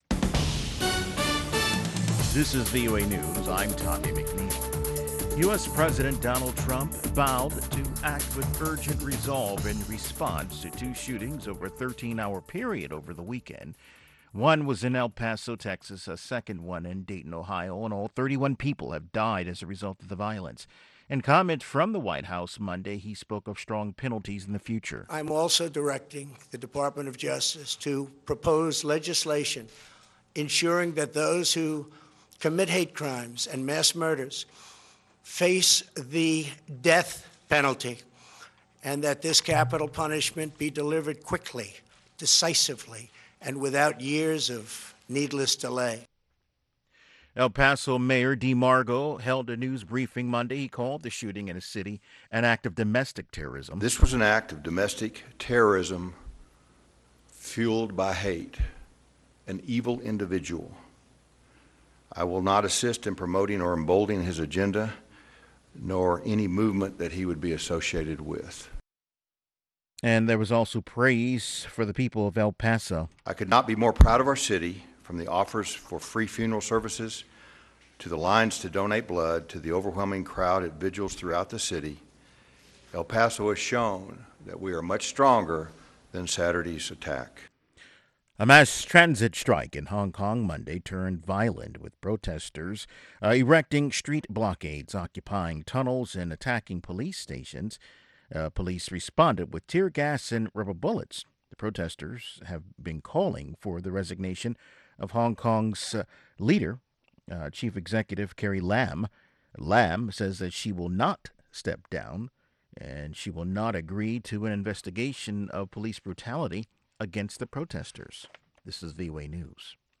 Around the clock, Voice of America keeps you in touch with the latest news. We bring you reports from our correspondents and interviews with newsmakers from across the world.